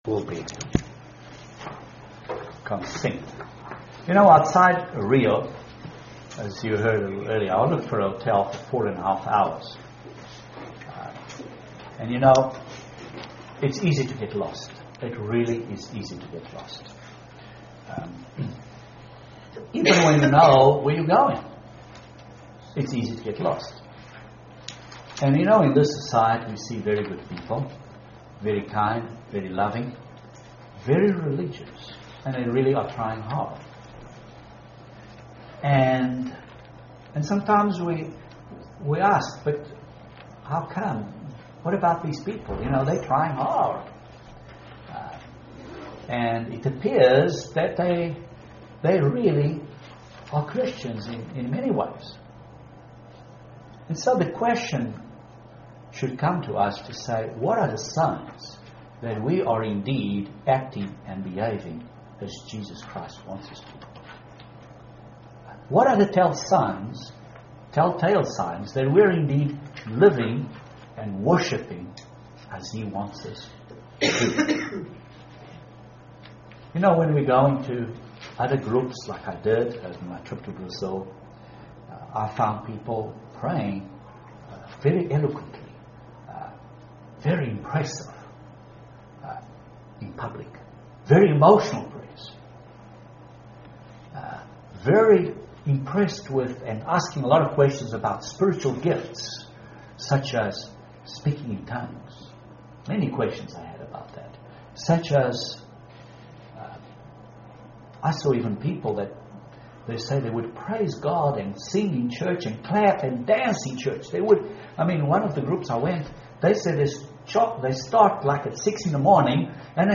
Some of the true signs that we are seeking the Kingdom of God first UCG Sermon Transcript This transcript was generated by AI and may contain errors.